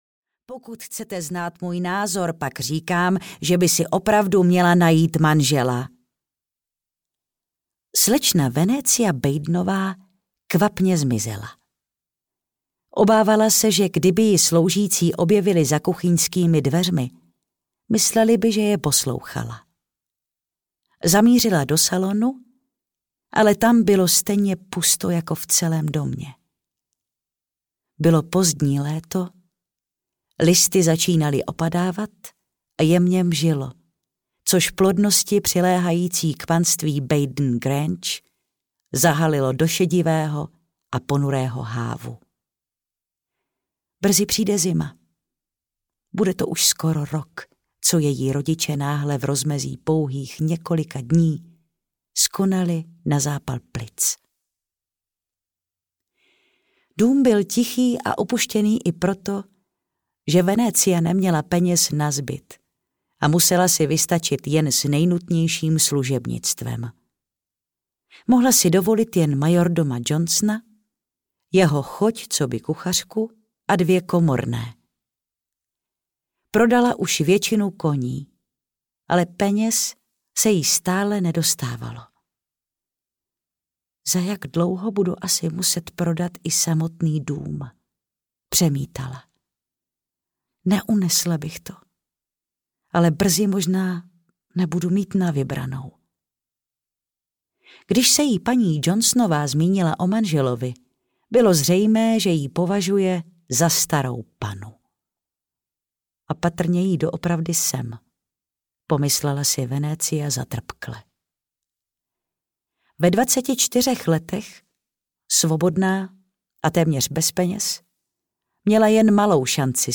Falešná nevěsta audiokniha
Ukázka z knihy